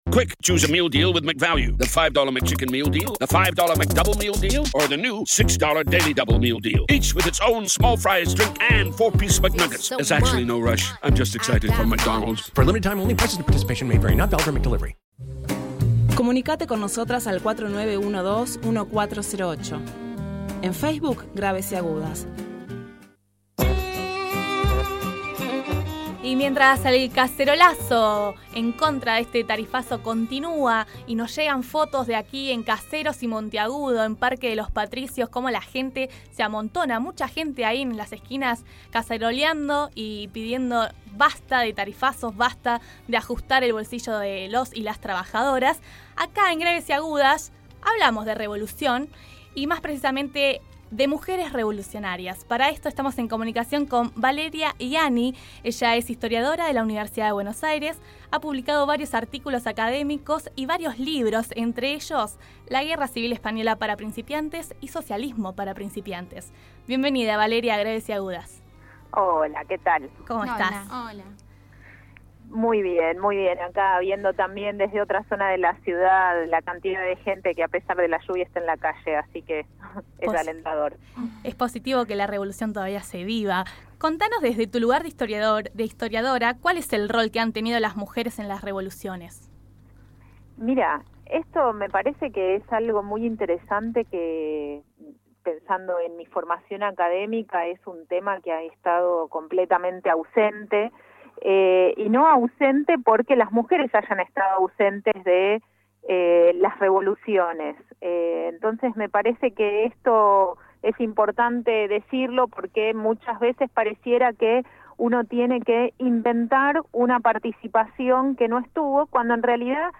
en conversación con Graves y agudas